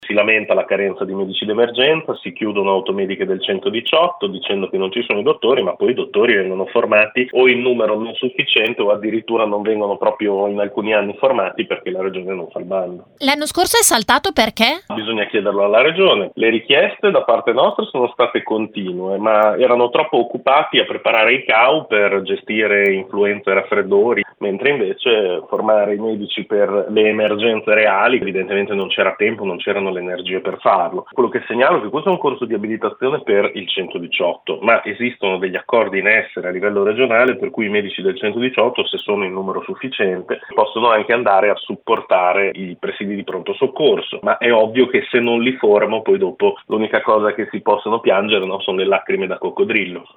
Nell’audio l’intervista